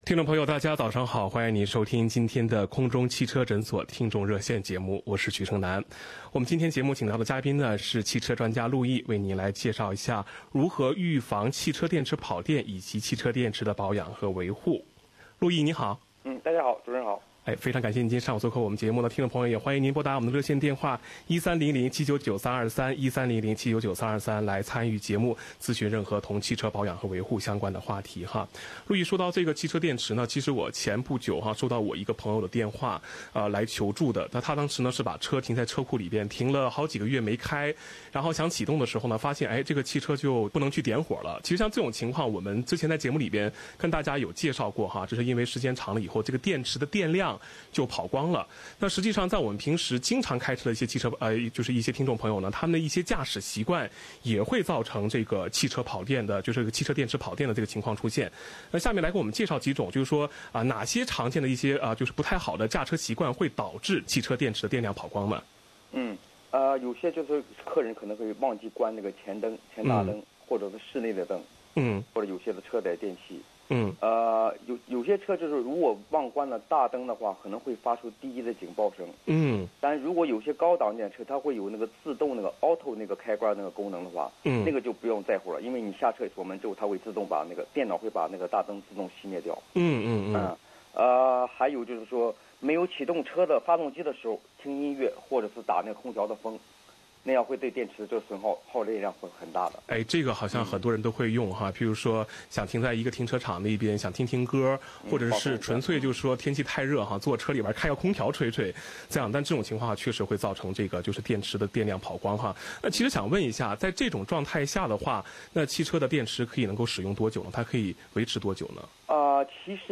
本期《空中汽车诊所-听众热线》节目